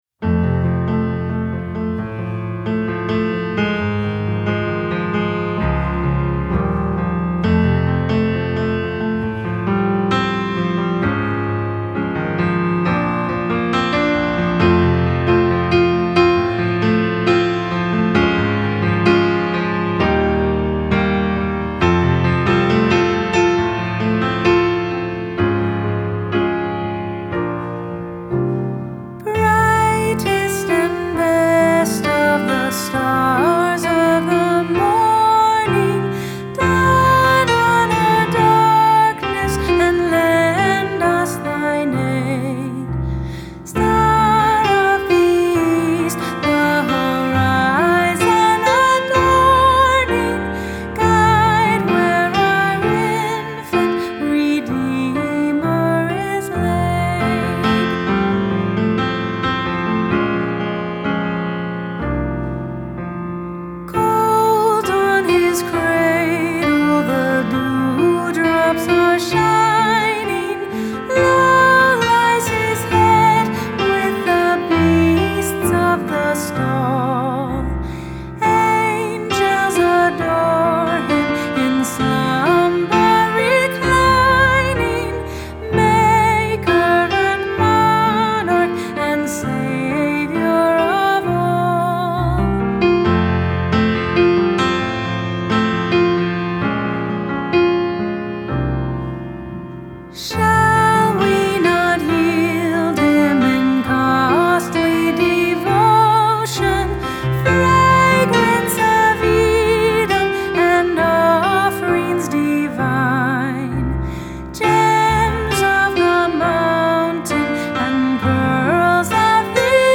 Hymn
The Hymnal Project